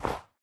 Sound / Minecraft / step / snow3